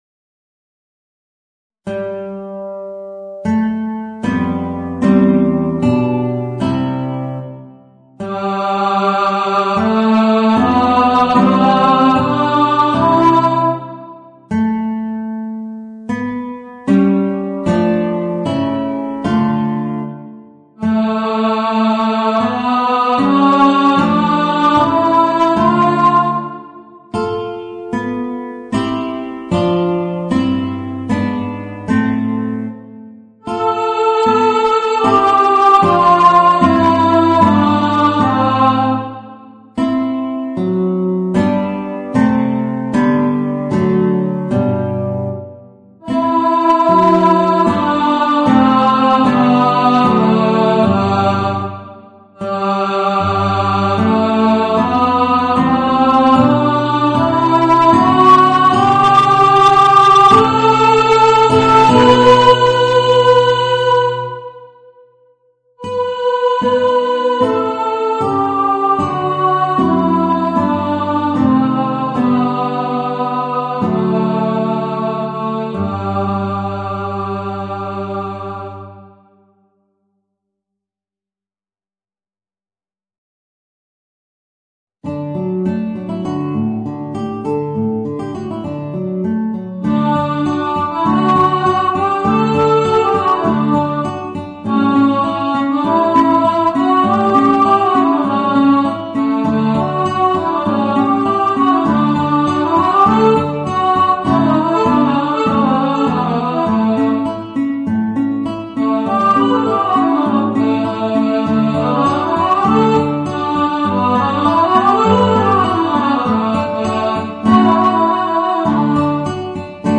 Voicing: Guitar and Alto